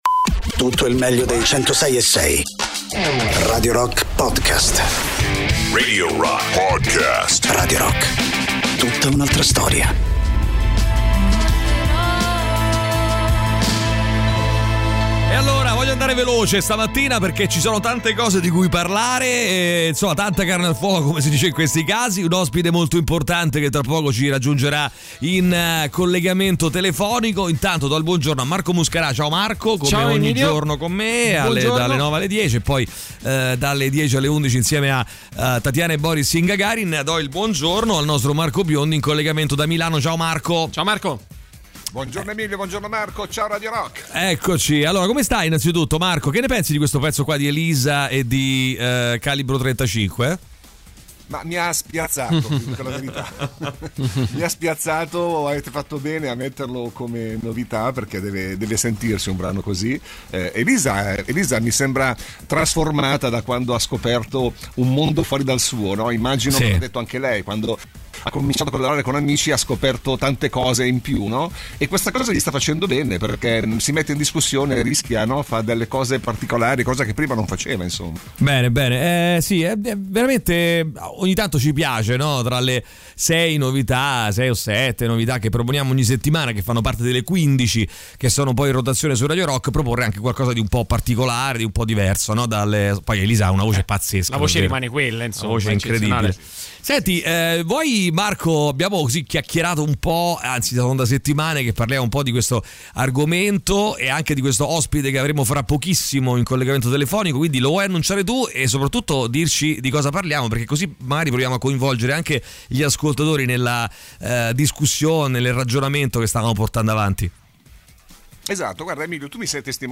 Ospite della Puntata: Eugenio Finardi.